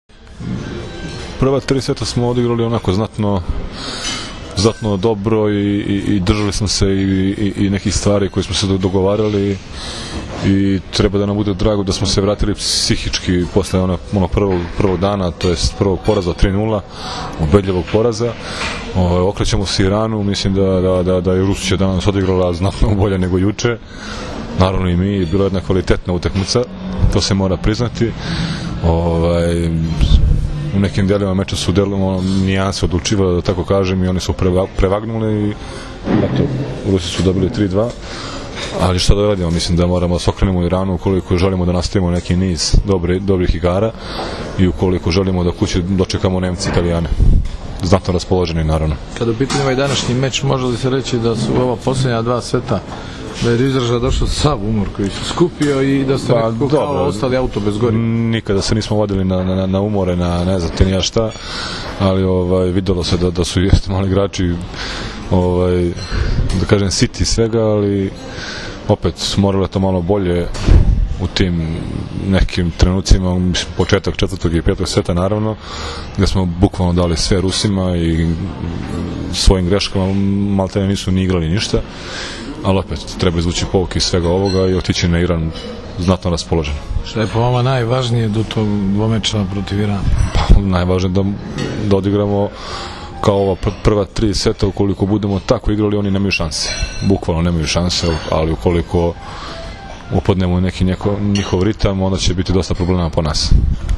IZJAVA VLADE PETKOVIĆA